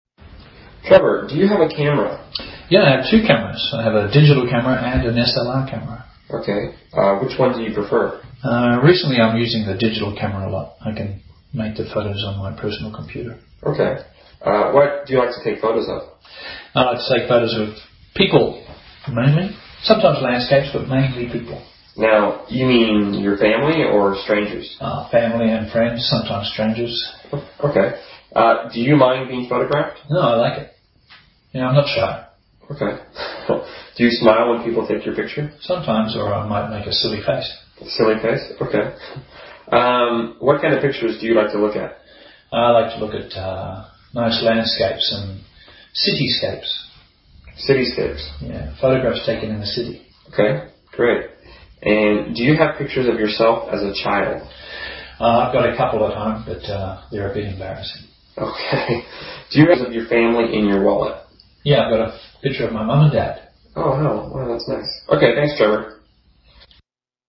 英语高级口语对话正常语速24:照相机（MP3）